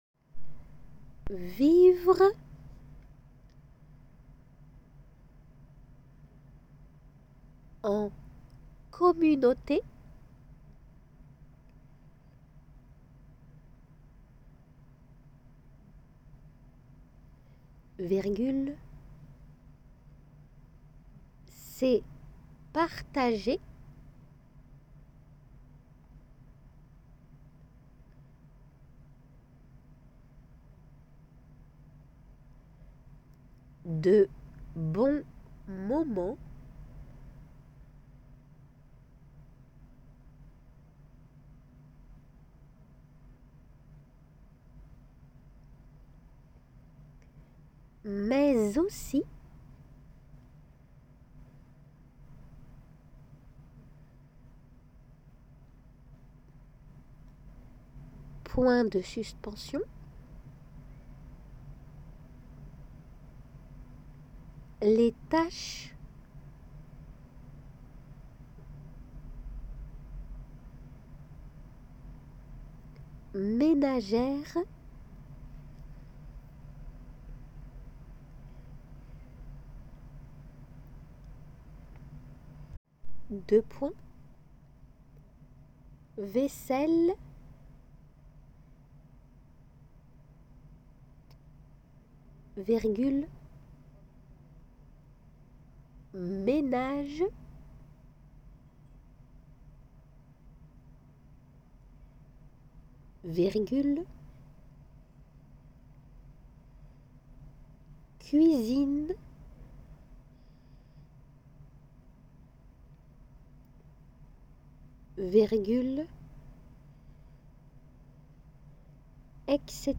聞き取り練習　練習用のデイクテ
デクテ用のの練習で